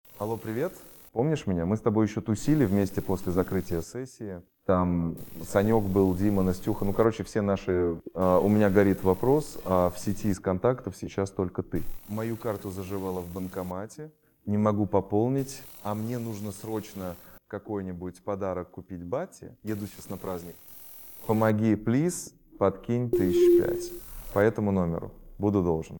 3. Странный фоновый шум
В звонках или голосовых сообщениях дипфейков может появиться неестественный фоновый шум, не похожий на обычные бытовые или уличные звуки. Он может внезапно обрываться или менять свою громкость в процессе.